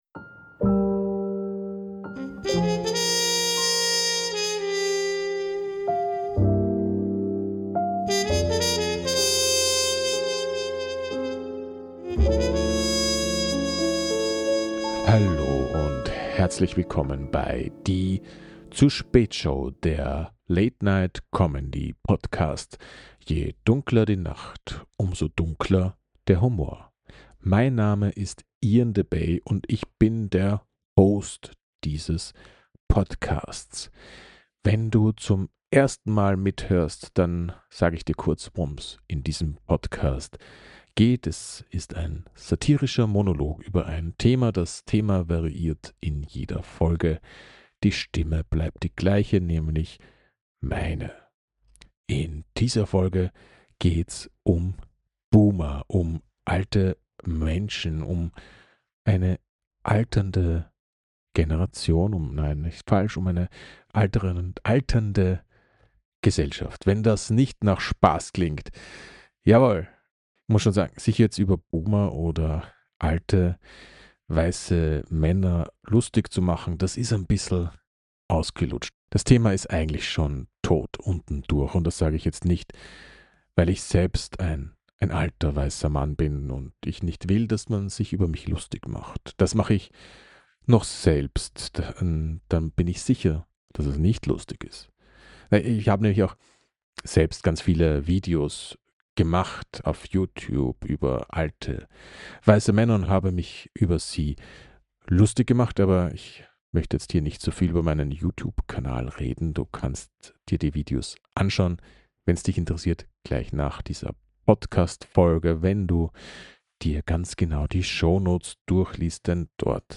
Ein Late Night Comedy Podcast. Gesellschaft, Politik, Klima, Alltag satirisch betrachtet, mit viel schwarzem Humor.